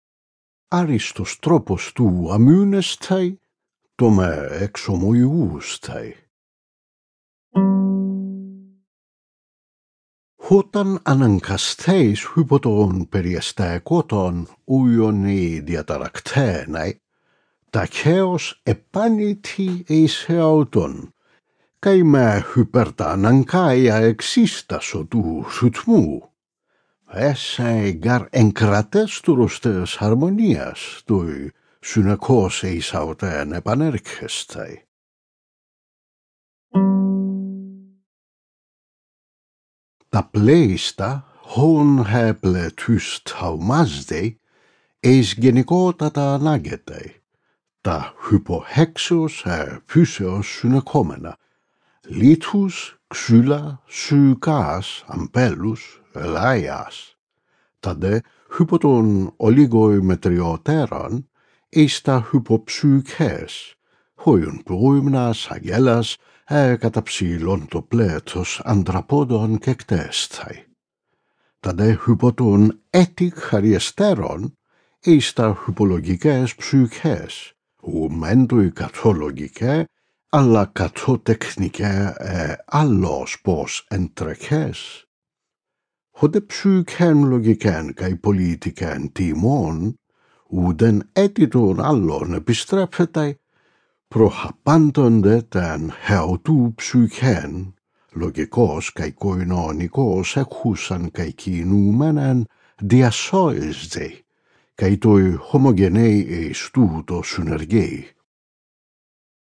Unabridged
You can listen to three fragments of “the Meditations, book 06”, an audio sample of the present recording.
audiobook a/o videobook of Marcus Aurelius Antoninus Meditations book 6, read in Ancient Greek